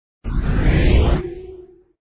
2_swoosh.mp3